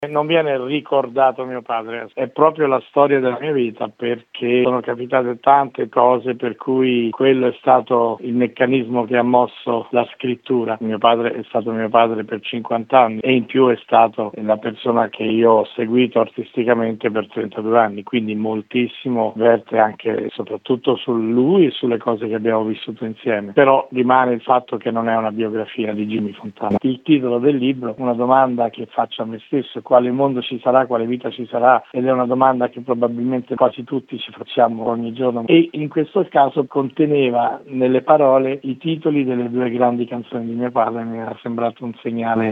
Il poliedrico artista ha parlato così della sua opera nell’intervista